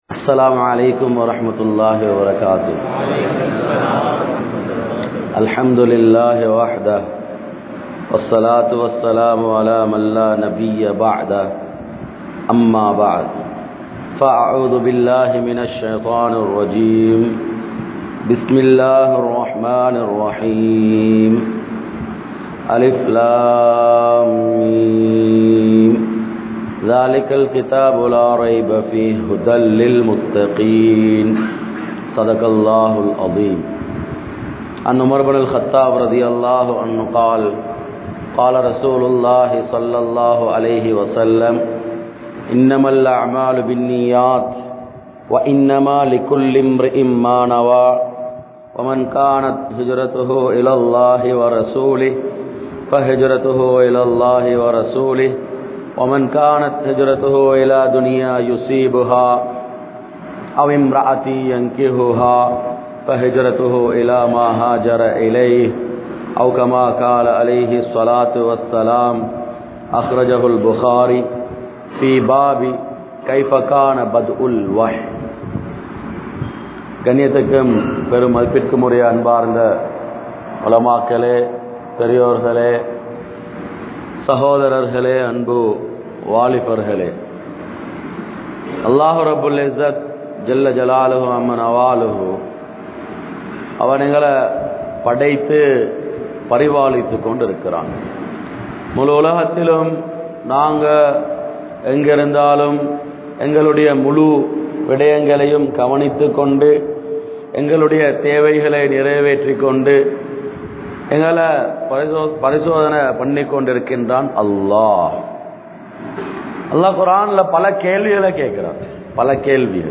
Suvanaththai Noakkiya Vaalifarhal (சுவனத்தை நோக்கிய வாலிபர்கள்) | Audio Bayans | All Ceylon Muslim Youth Community | Addalaichenai
Colombo15, Zaviya Lane, Zaviyathul Khairiya Jumua Masjidh